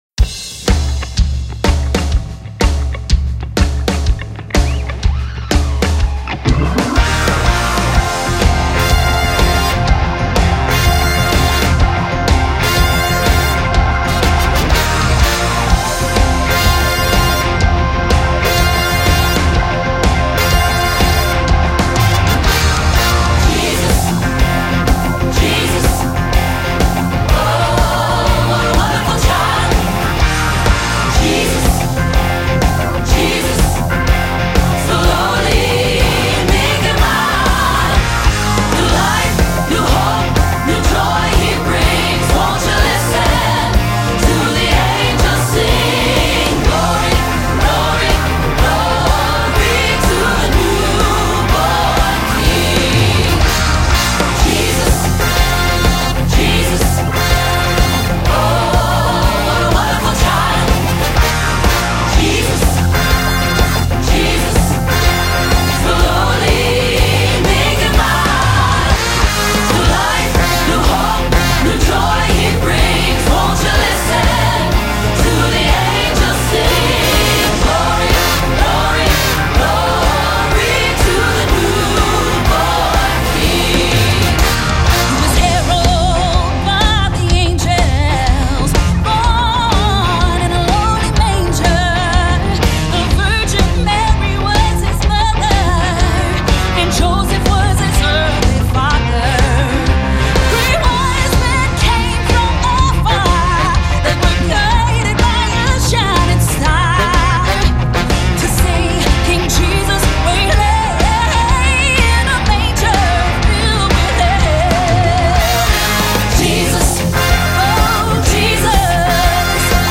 There are full tracks to listen to, and individual tracks for each voice part.